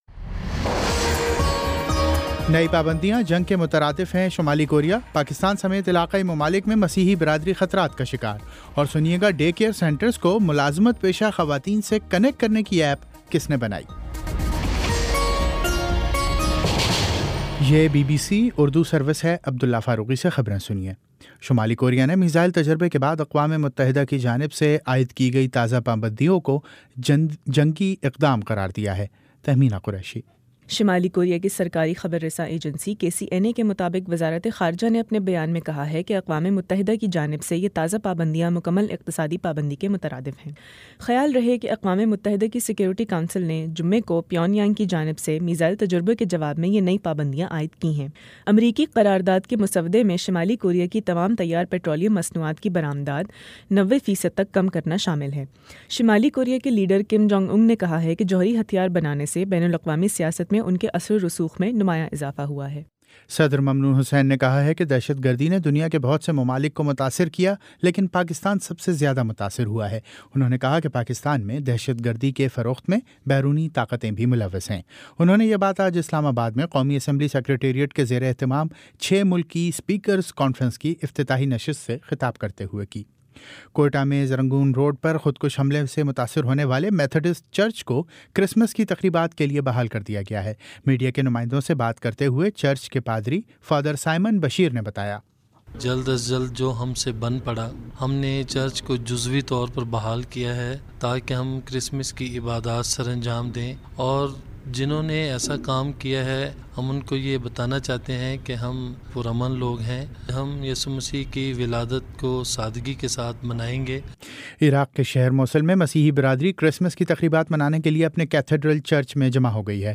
دسمبر 24 : شام چھ بجے کا نیوز بُلیٹن